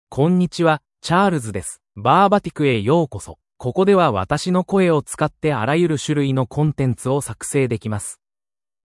MaleJapanese (Japan)
CharlesMale Japanese AI voice
Charles is a male AI voice for Japanese (Japan).
Voice sample
Listen to Charles's male Japanese voice.